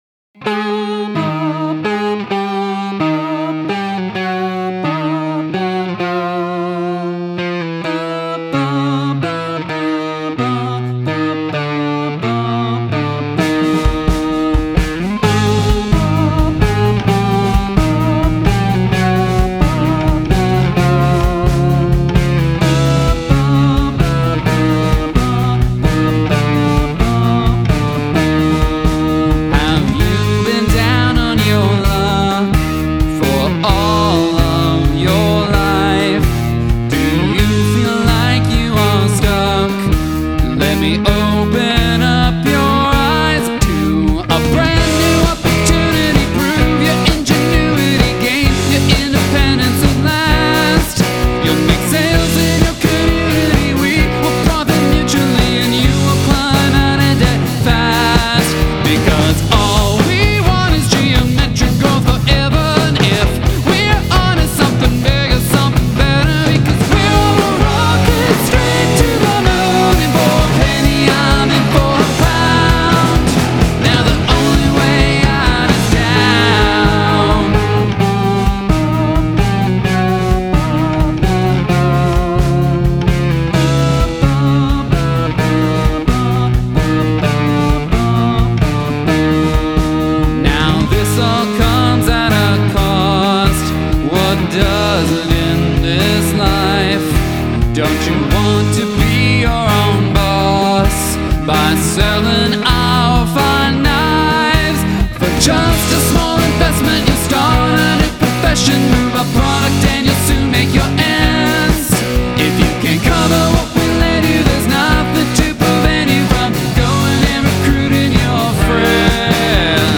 Downward Modulation